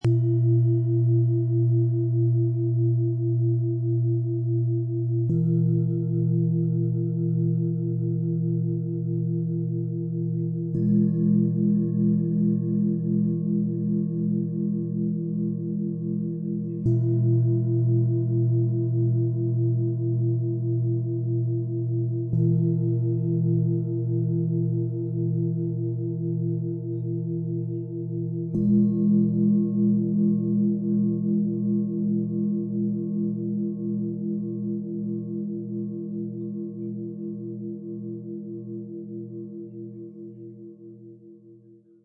Set aus 3 Planetenschalen für Klangpädagogik, Klangmeditation & Klangmassage, Ø 16,1 - 21,2 cm, 2,57 kg
Emotionale Sicherheit, Ausdruck und Erneuerung schwingen in diesem fein abgestimmten Dreiklang.
Mit überliefertem Wissen formen erfahrene Handwerker jede Schale - für tiefe Resonanz und klare Schwingung.
Mit unserem Sound-Player - Jetzt reinhören können Sie den Original-Klang dieser drei handverlesenen Schalen direkt anhören.
Tiefster Ton: Mond
Bengalen Schale, Glänzend, 21,2 cm Durchmesser, 9,2 cm Höhe
Mittlerer Ton: Hopi, Mond
Höchster Ton: Uranus, Mars